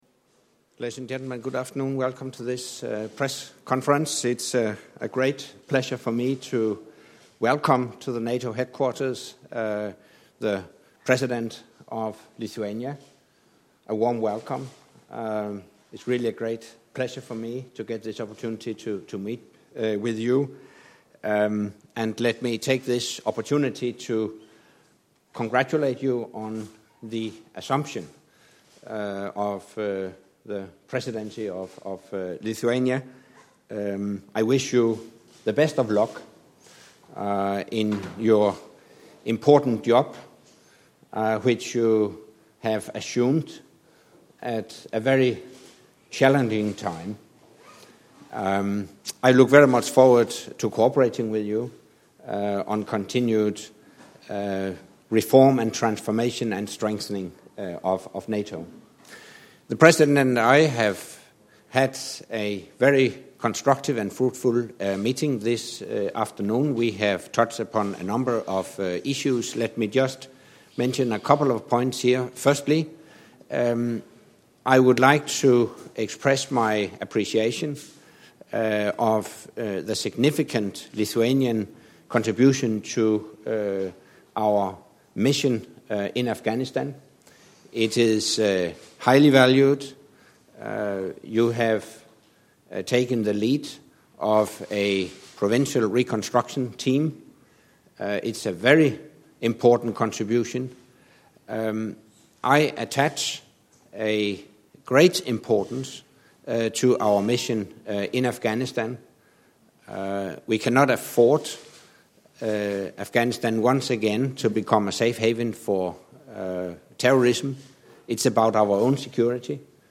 Joint press point with NATO Secretary General Anders Fogh Rasmussen and the President of Lithuania